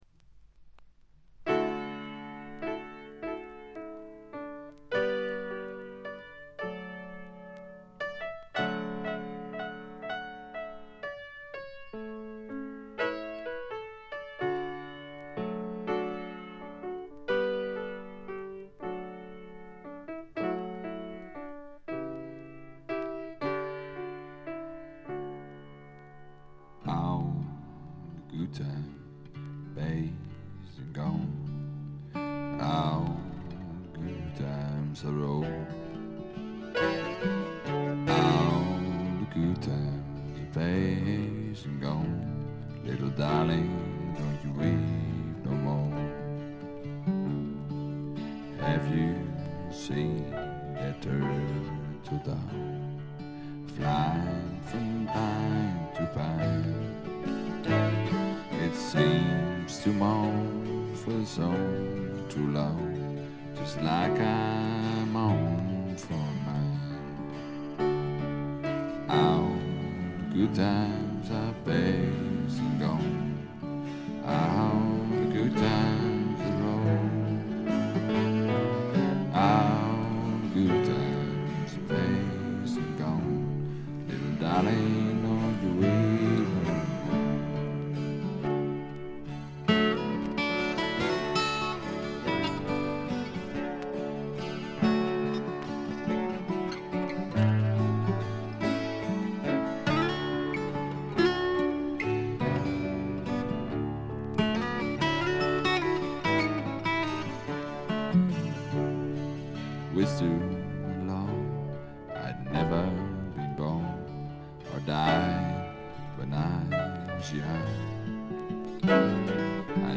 部分試聴ですがほとんどノイズ感無し。
内容は激渋のアコースティック・ブルース。
試聴曲は現品からの取り込み音源です。